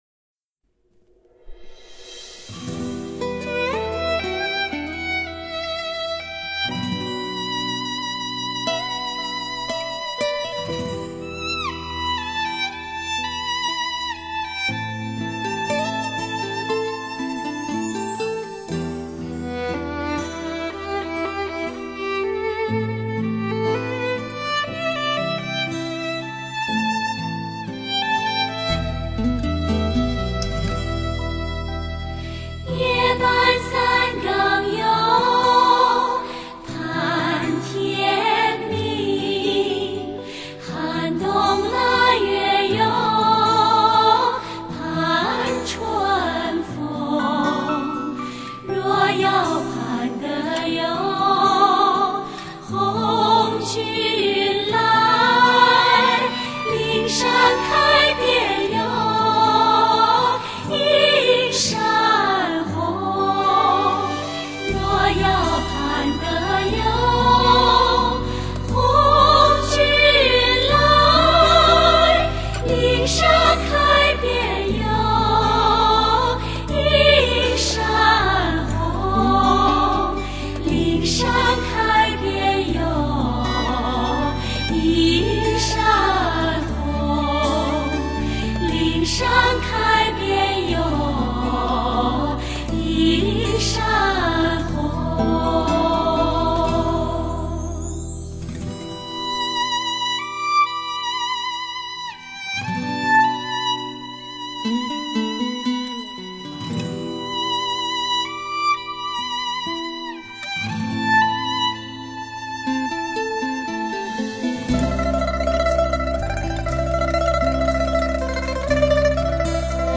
这是一张非常有民歌味道的民歌专辑，歌曲演绎清新、自然、流畅，人声效果一流。